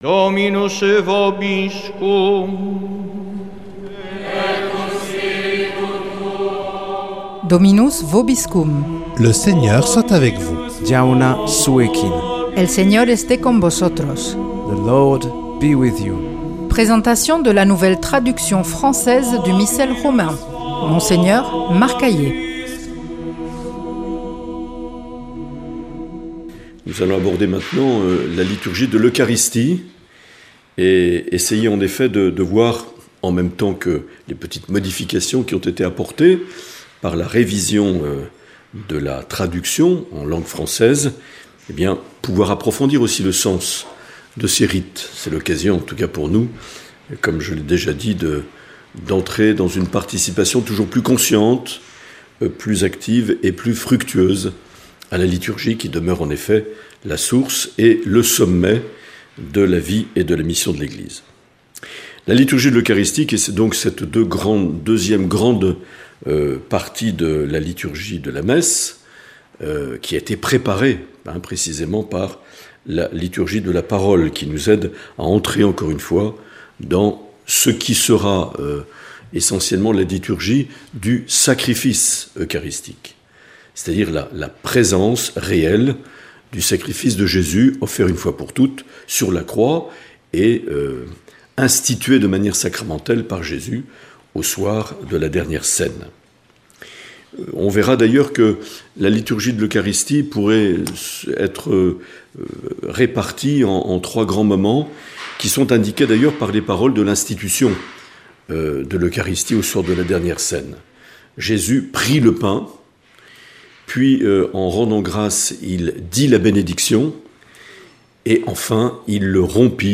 Présentation de la nouvelle traduction française du Missel Romain par Mgr Marc Aillet
Monseigneur Marc Aillet